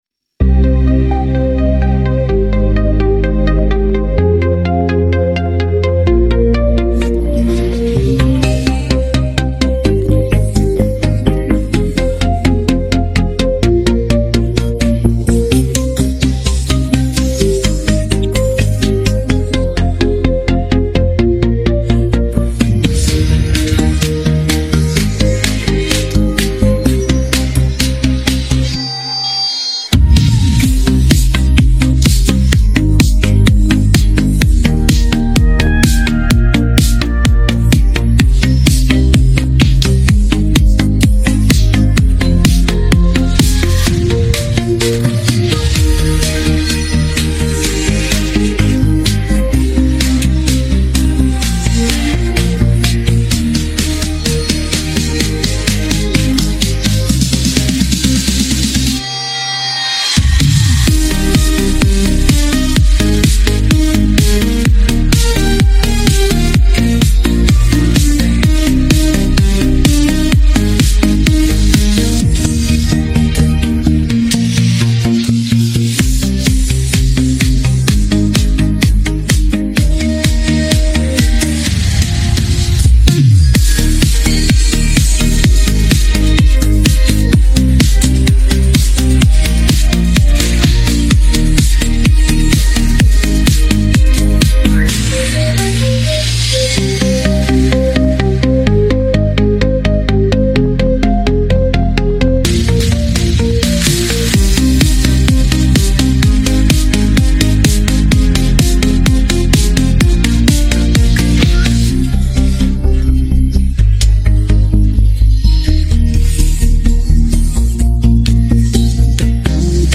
Creo en Suardi instrumental.mp3